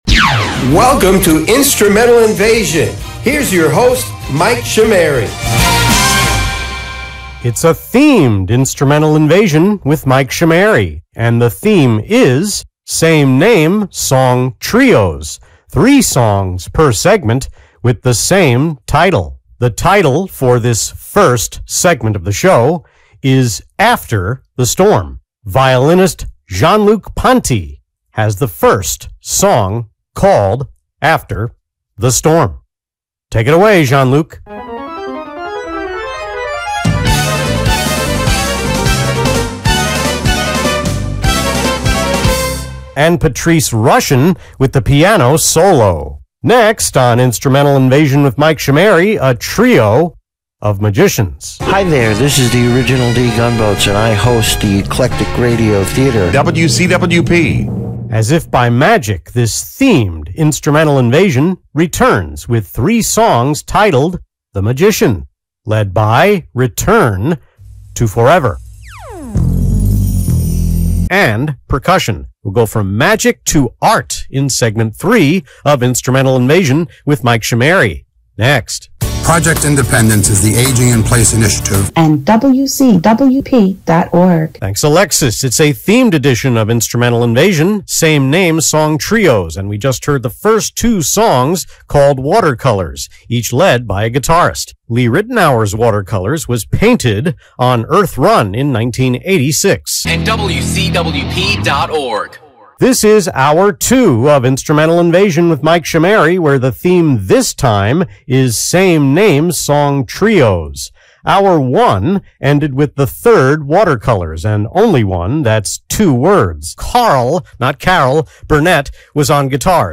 10/12 UPDATE: Here are the evergreen talk break variations heard last night: